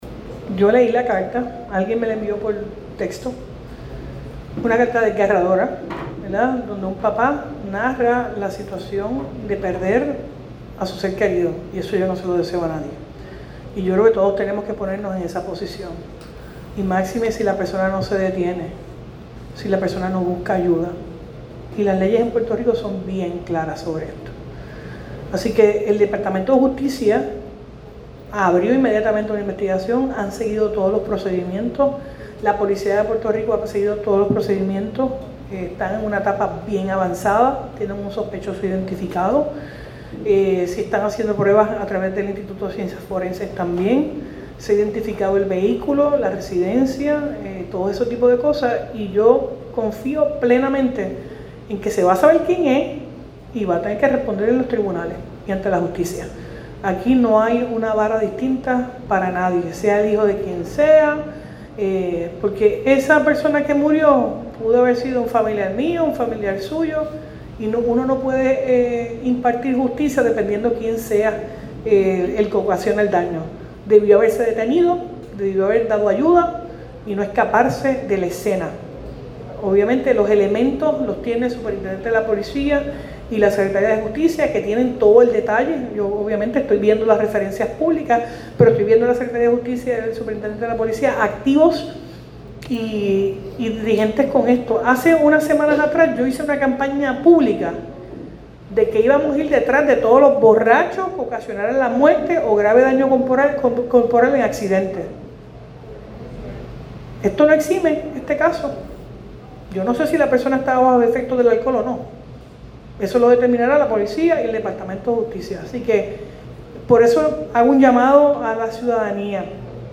La gobernadora Jenniffer González aseguró que la muerte de un joven gruero que fue atropellado en la PR-52 por un conductor que se fue a la huida, está siendo investigada con transparencia.